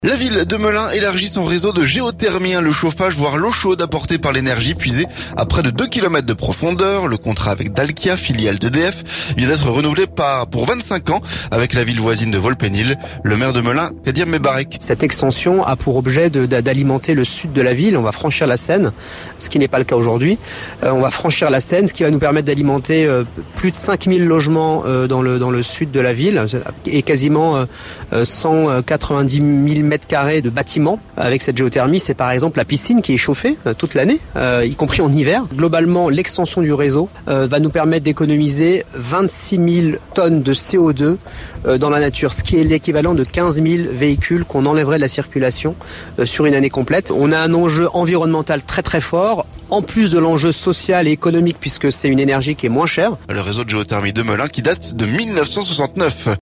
Le contrat avec Dalkia, filiale d'EDF, vient d'être renouvelé pour 25 ans, avec la ville voisine de Vaux-le-pénil. Le maire de Melun Kadir Mebarek.